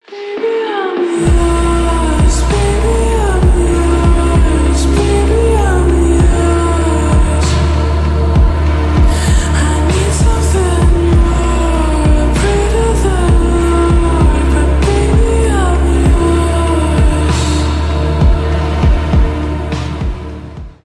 медленные
поп , романтические